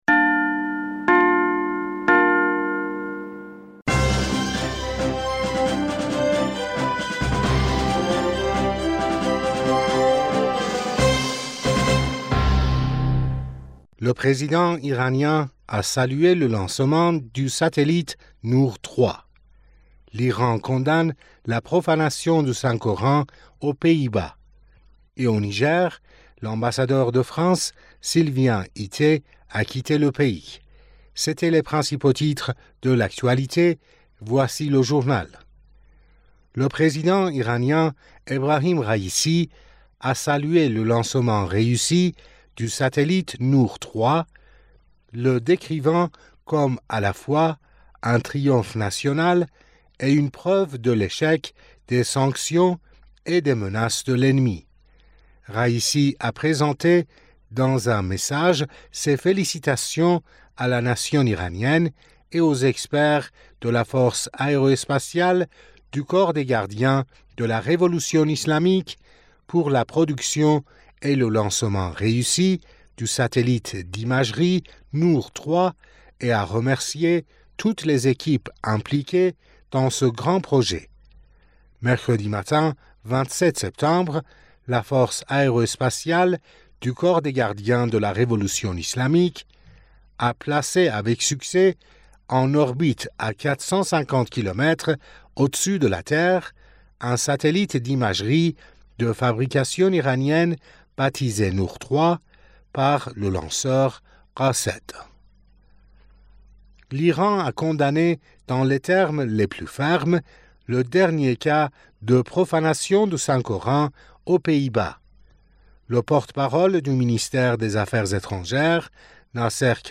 Bulletin d'information du 28 Septembre 2023